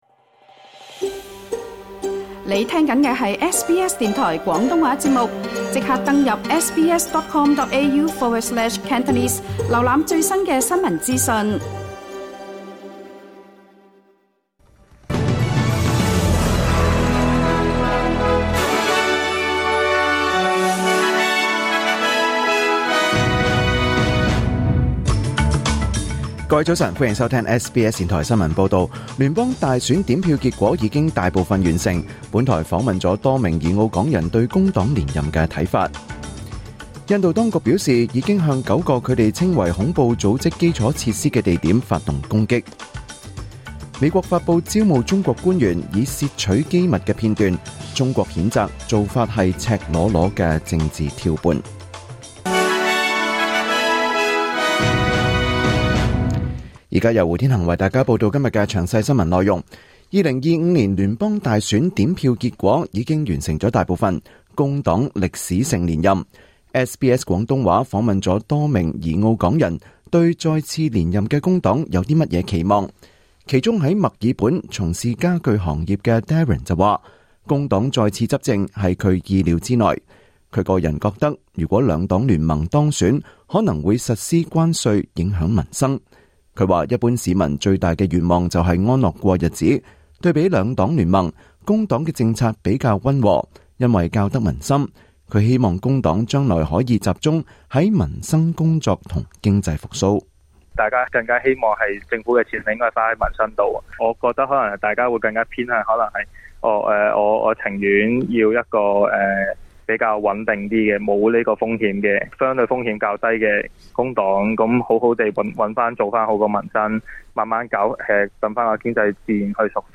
2025年5月7日SBS 廣東話節目九點半新聞報道。